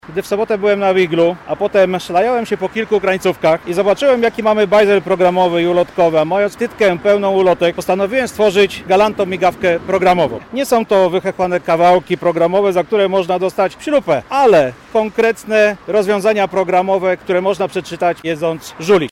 Krzysztof Makowski w niecodzienny sposób wspomniał o łodzianizmach. Chce w ten sposób zadbać o specyficzny język znanym tylko łodzianom.